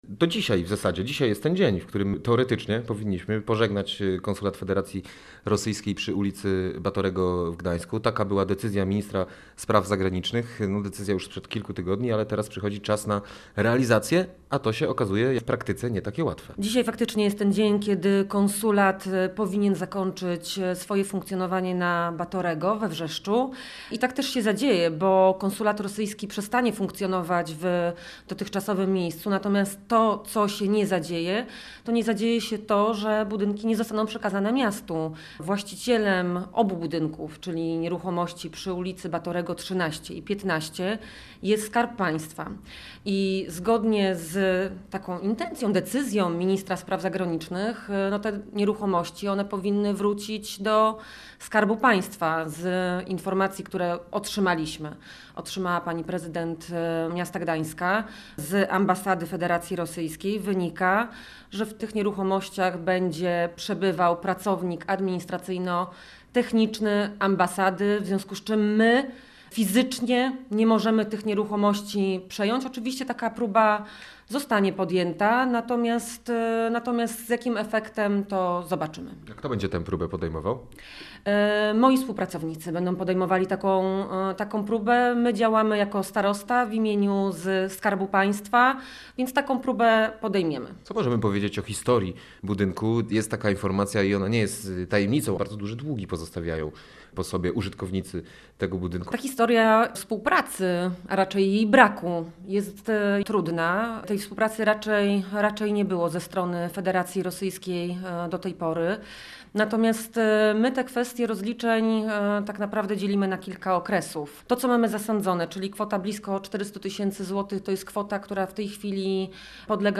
Będzie próba polubownego przejęcia od Federacji Rosyjskiej budynku konsulatu rosyjskiego – przyznała w rozmowie z Radiem Gdańsk wiceprezydent Gdańska Emilia Lodzińska.